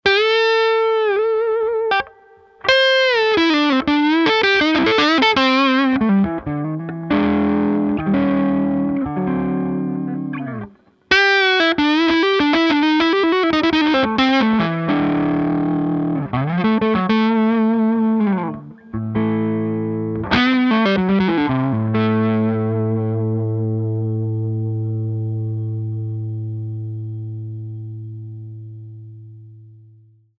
"‘Stoop' is my 2001 R9 Les Paul and ‘HRF' is a 1998 Howard Roberts Fusion. These two tracks are nothing but the amp and the guitars and a Shure 57.